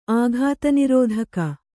āghāta nirōdhaka